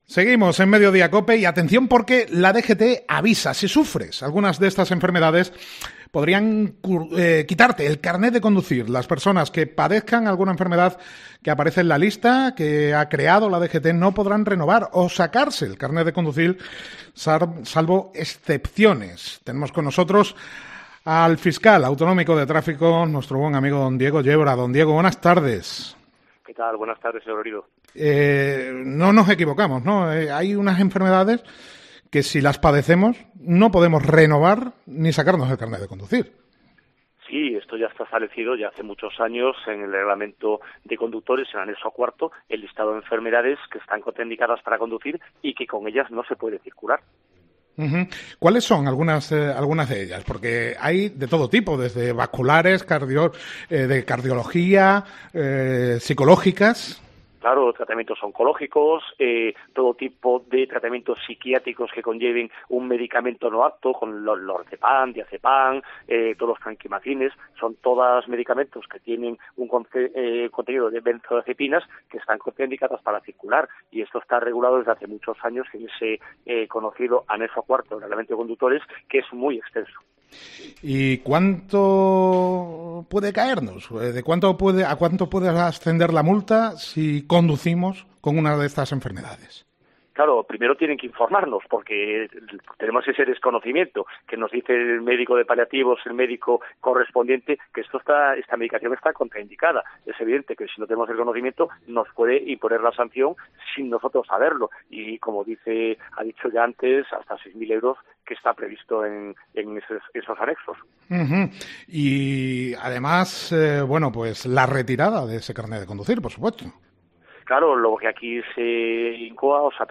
Hablamos con el Fiscal Autonómico de Tráfico, Diego Yebra